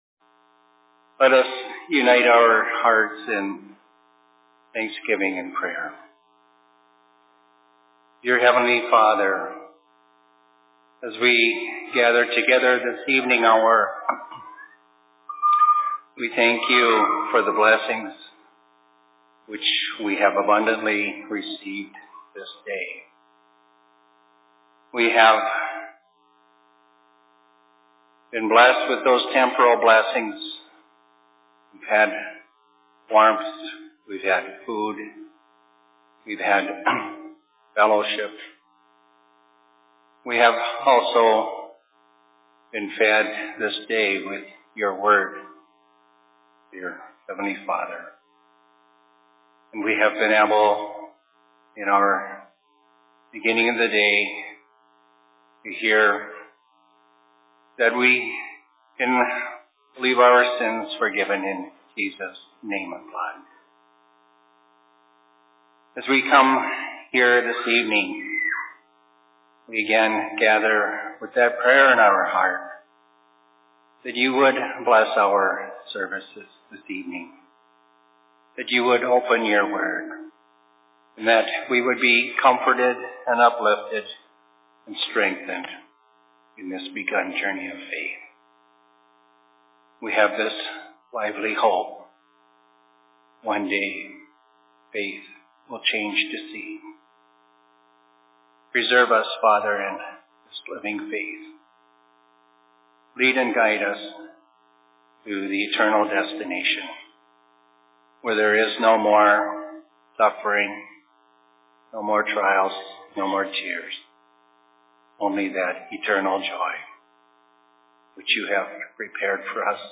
Sermon in Rockford 12.11.2017
Location: LLC Rockford